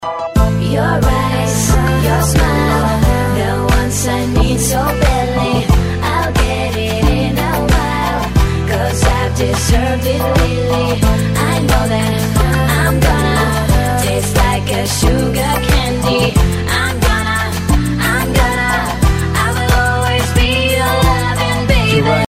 Романтические рингтоны
романтичные приятный голос